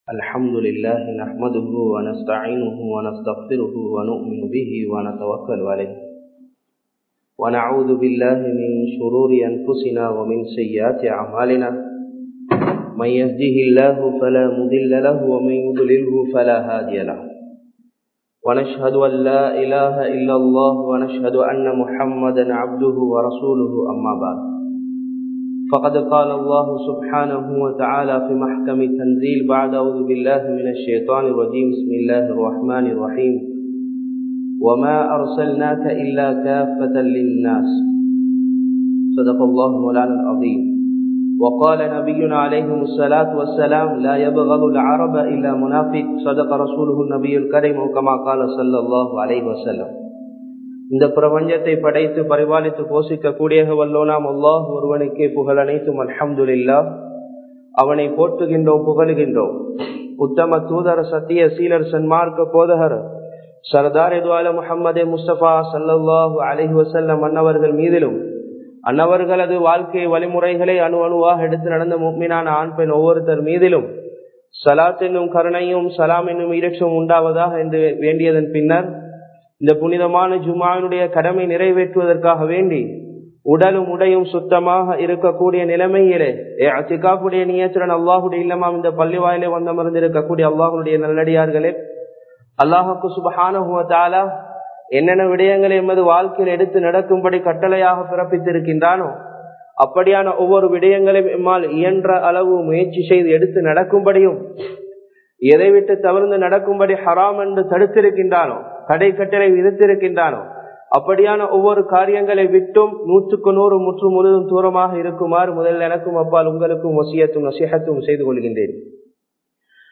நபியவர்களின் பிறப்பின் அதிசயம் (Wonderness on birth of Prophet (SAW)) | Audio Bayans | All Ceylon Muslim Youth Community | Addalaichenai
Kandy, Dhehiyanga, Muhiyadeen Jumua Masjidh 2020-10-23 Tamil Download